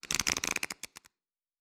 Cards Shuffle 2_09.wav